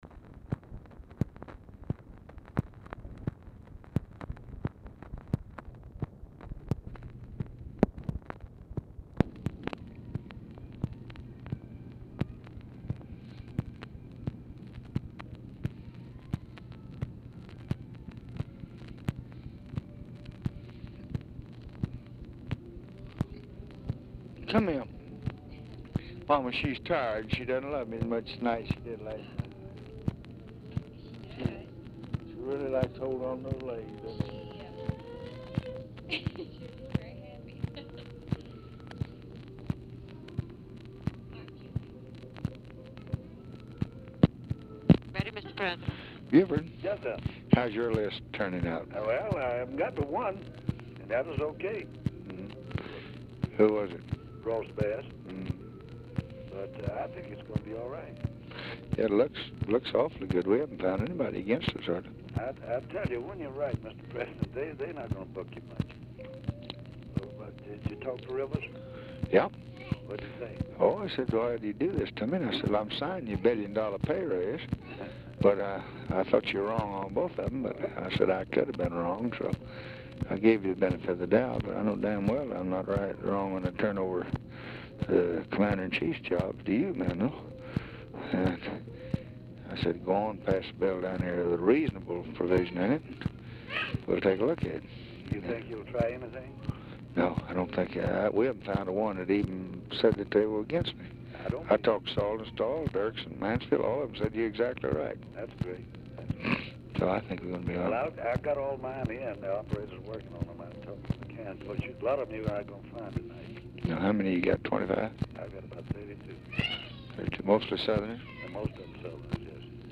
Telephone conversation # 8606, sound recording, LBJ and BUFORD ELLINGTON
OFFICE CONVERSATION PRECEDES CALL
MUSIC AUDIBLE IN BACKGROUND
Format Dictation belt